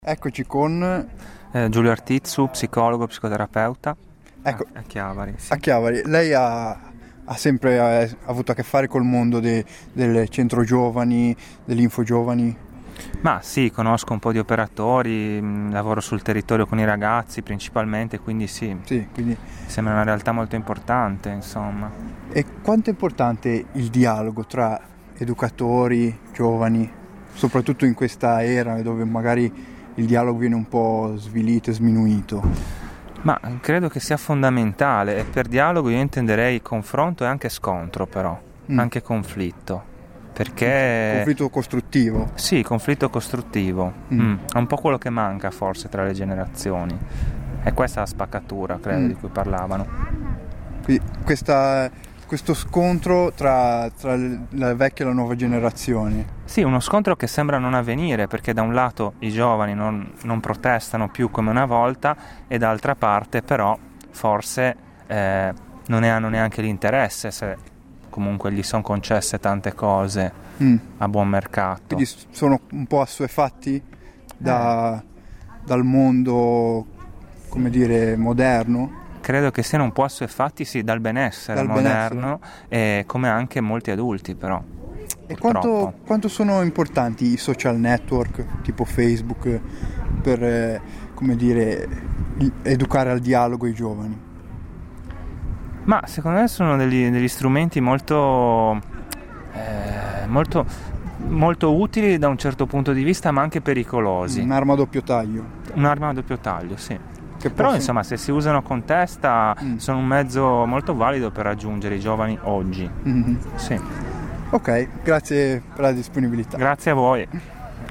Intervista a Passante